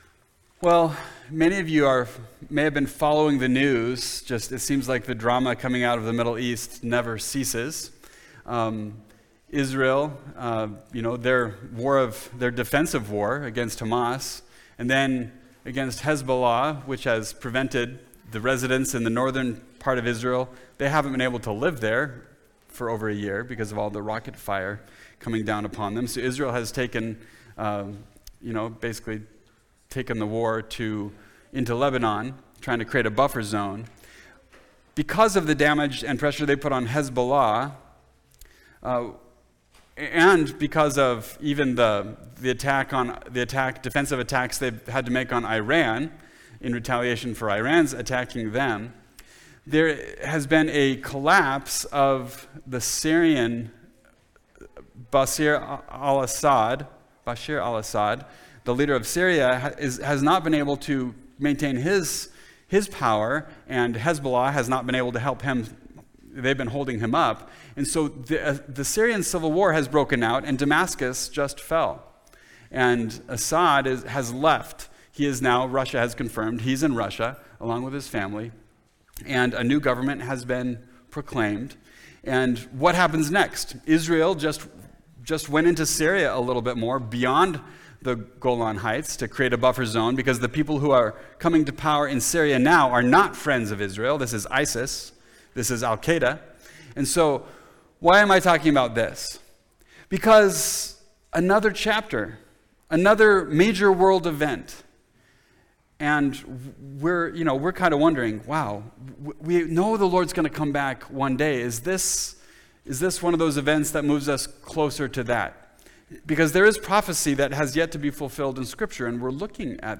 Christmas Message